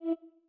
rotate.wav